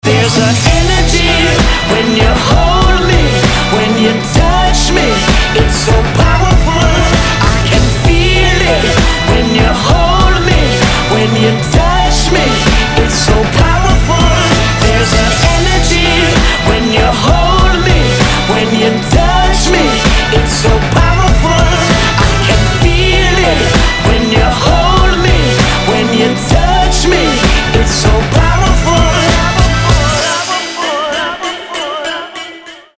• Качество: 320, Stereo
Electronic
Электронная коллабрация